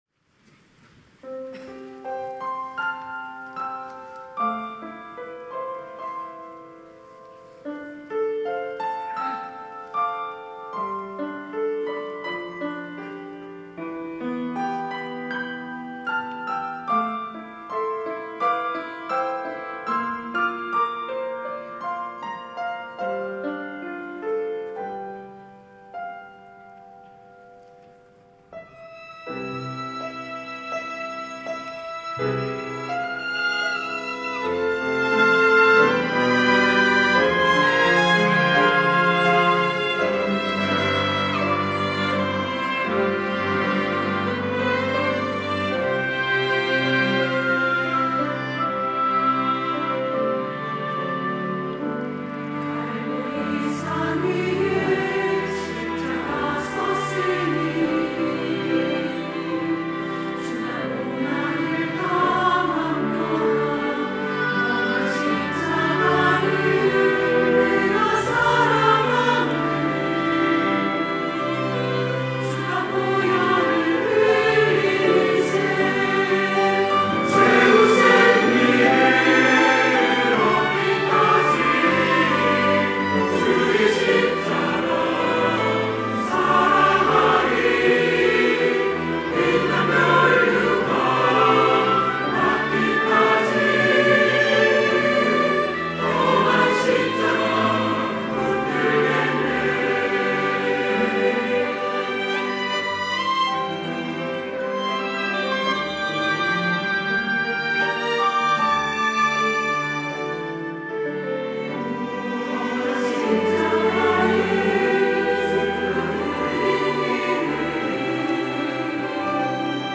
호산나(주일3부) - 험한 십자가
찬양대